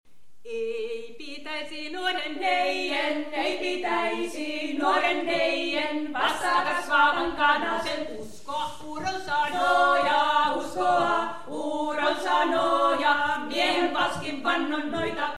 Ei pitäisi nuoren neijen (Kuhmon Kalevalakylä 2004). Laulaja päivittelee epäonnistuneita naimakauppojaan.